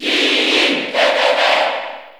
Category: Crowd cheers (SSBU) You cannot overwrite this file.
King_Dedede_Cheer_Italian_SSB4_SSBU.ogg